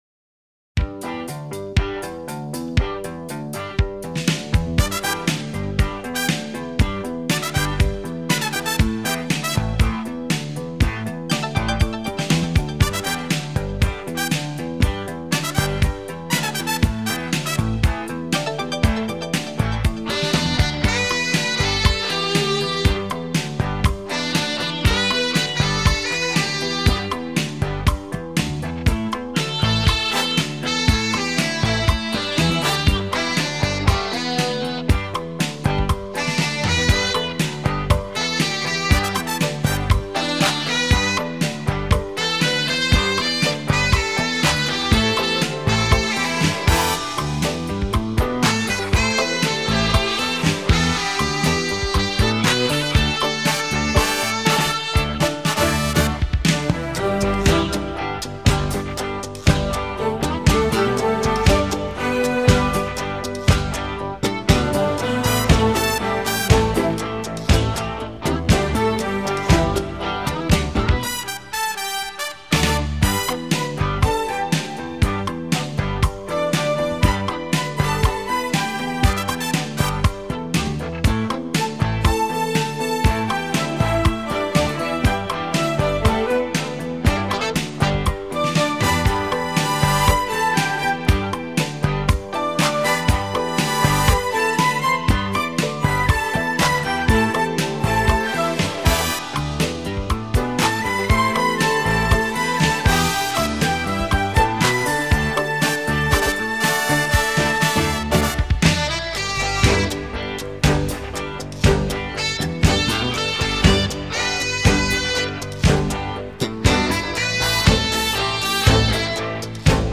清新华丽，瑰丽浪漫迷人的情调音乐使者。
形成了他那将优美的旅律，生动的节奏和明朗的气氛结合起来的独特风格。
每首曲子均有相当神秒的技巧，瑰丽的音乐效果，优雅迷人的气氛，